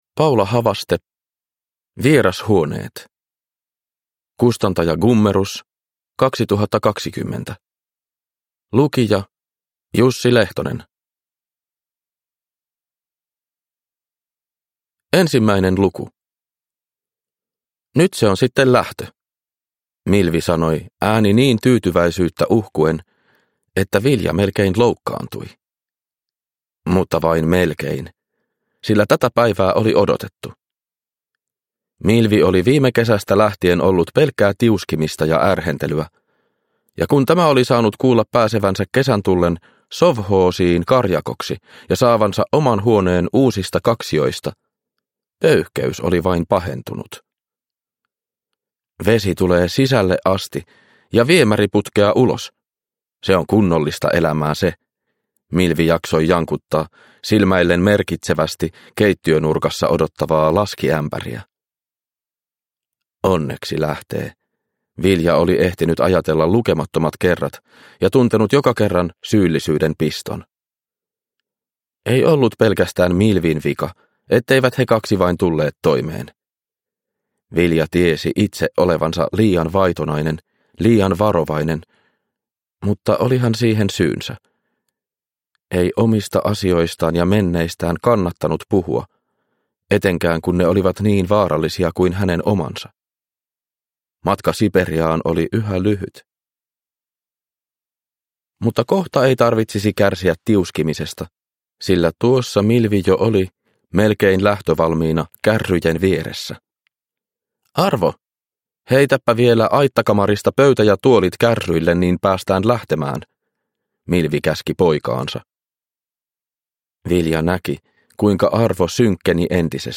Vierashuoneet – Ljudbok – Laddas ner